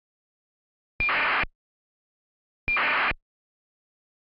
ACARS 131,550
В Нижнем Новгороде принят сигнал ACARS на частоте 131,550 не свойственной для данного региона. декодировать не могу, прога не встаёт на 64-х битную систему.